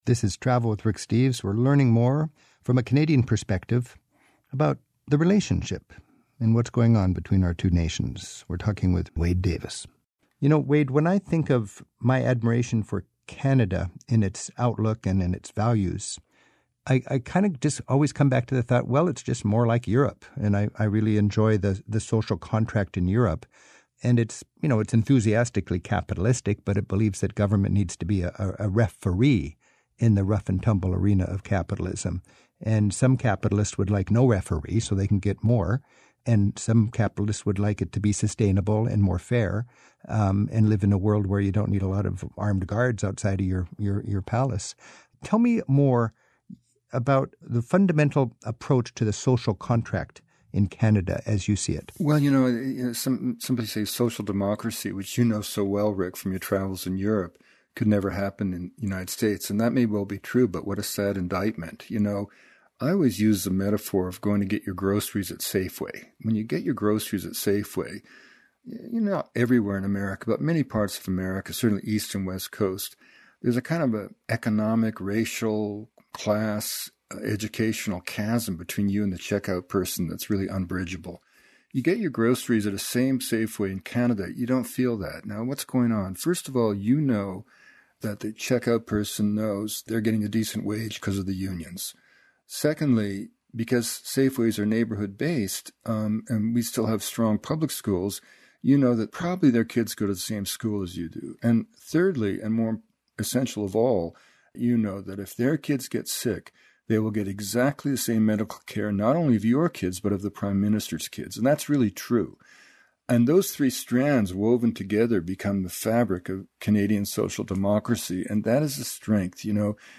More with Wade Davis - Anthropologist Wade Davis explains how Canada aims to provide an egalitarian social contract with its citizens. He adds an inspiring bit of advice he once got from the late Hockey Night in Canada announcer Bob Cole.